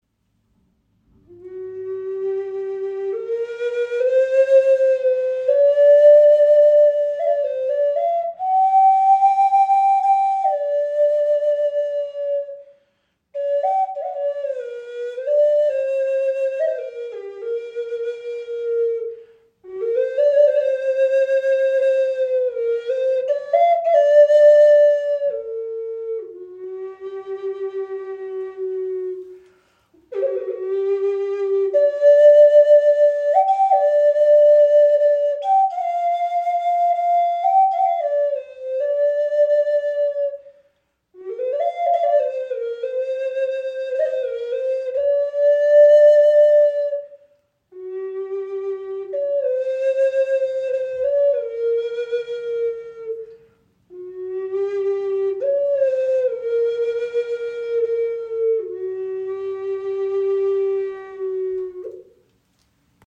Okarina aus Wurzelstück | G4 in 432 Hz | Pentatonische Stimmung | ca. 20 cm
Klein, handlich, klangvoll – eine Okarina mit Seele
In pentatonischer G4 Moll Stimmung auf 432 Hz gestimmt, entfaltet sie einen warmen, klaren Klang, der Herz und Seele berührt.
Trotz ihrer handlichen Grösse erzeugt sie einen angenehm tiefen und warmen Klang – fast ebenbürtig zur nordamerikanischen Gebetsflöte.